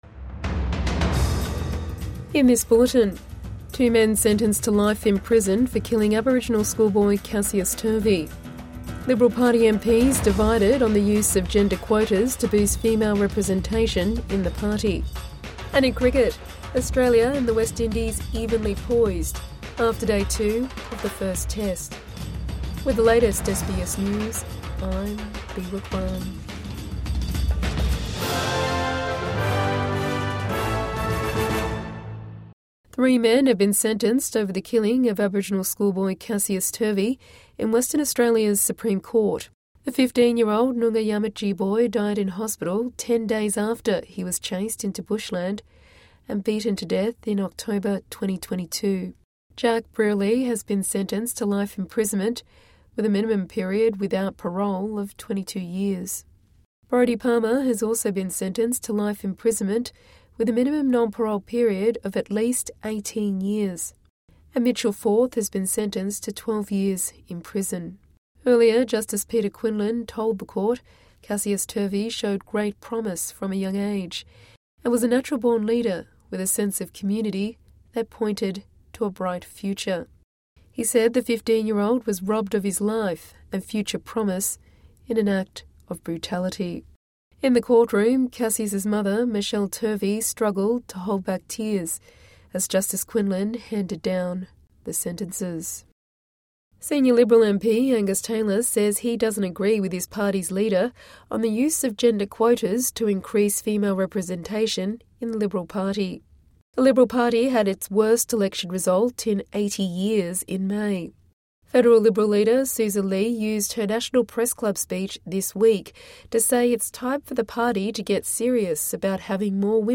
Evening News Bulletin 27 June 2025 | SBS News